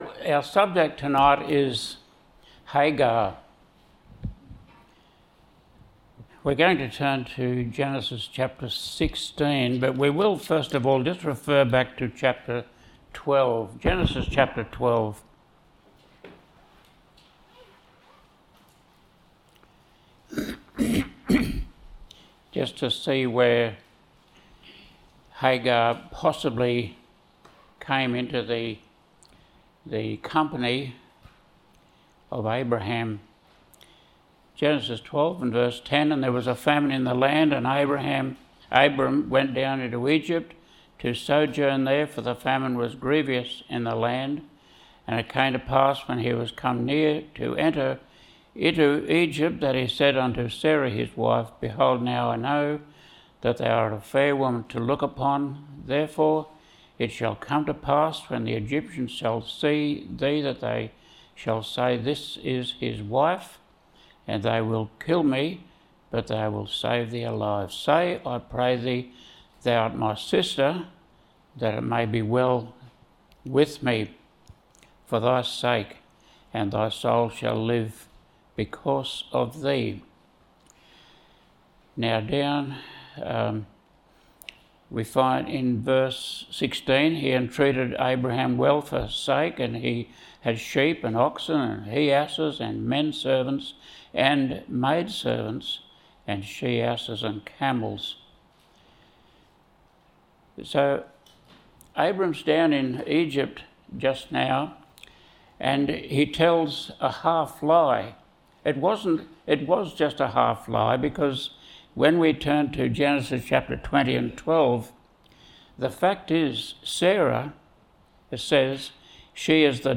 Scripture readings: Gen 12:10-16; ch16:7, 13 Location: Cooroy Gospel Hall (Cooroy, QLD, Australia)